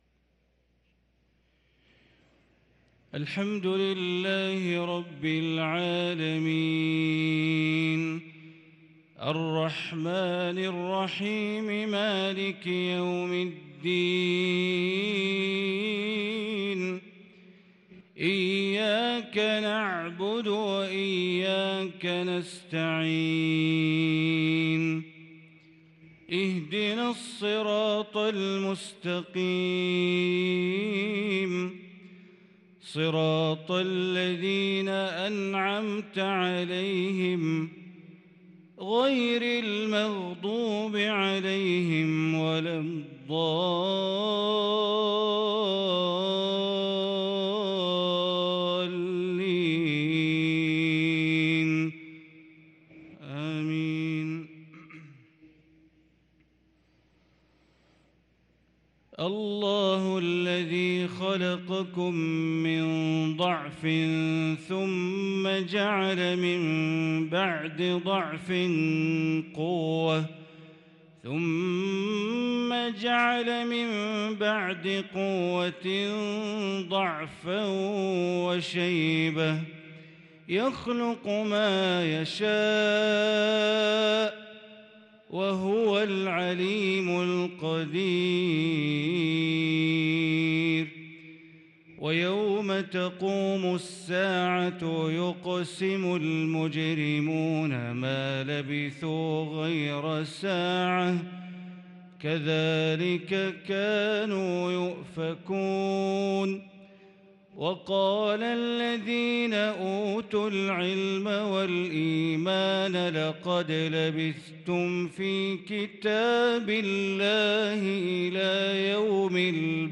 صلاة العشاء للقارئ بندر بليلة 19 ذو الحجة 1443 هـ